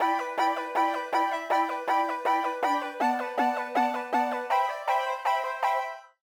BackYard Melody 5 (Plucks).wav